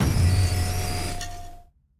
Cri de Pelage-Sablé dans Pokémon Écarlate et Violet.
Cri_0989_EV.ogg